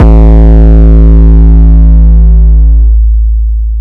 lex siz 808.WaV.wav